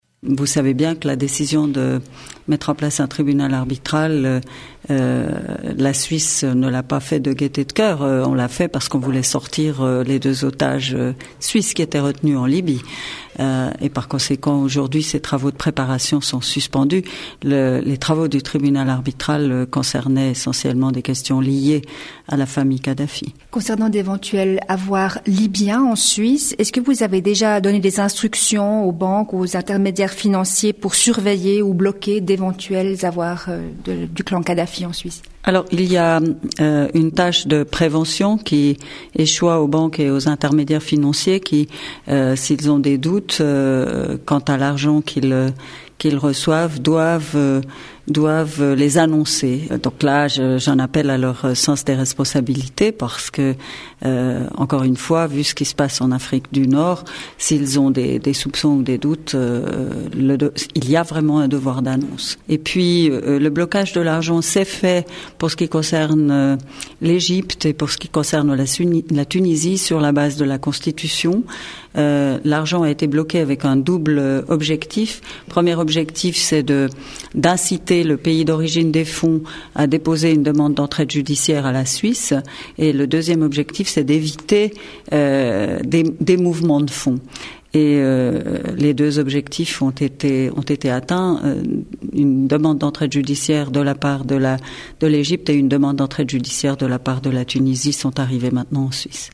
Micheline Calmy-Rey, présidente de la Confédération